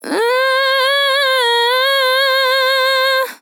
TEN VOCAL FILL 27 Sample
Categories: Vocals Tags: dry, english, female, fill, sample, TEN VOCAL FILL, Tension